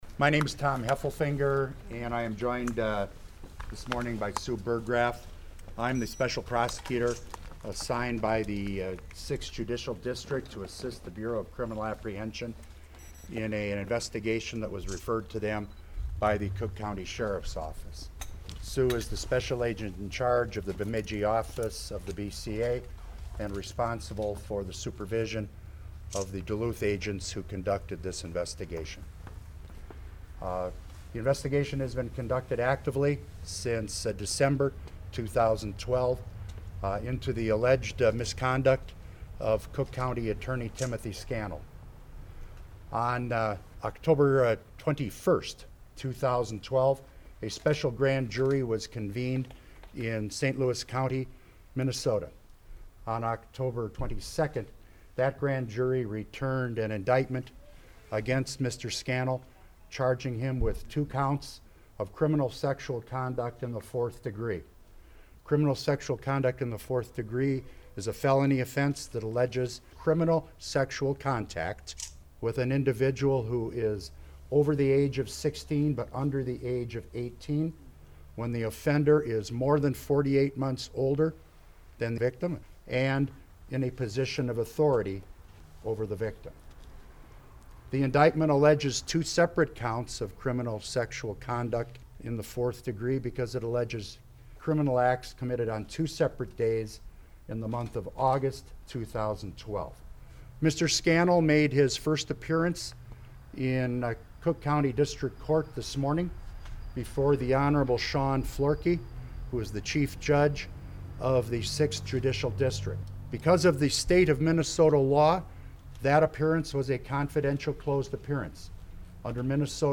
Full audio of Heffelfinger news conference
Attachment Size FullHeffelfingerConf_103113.mp3 26.74 MB Special Cook County Prosecutor Thomas Heffelfinger held a news conference the morning of Oct. 31 regarding the charges brought against Cook County Attorney Tim Scannell. The conference took place at 10 a.m. on the steps of the courthouse.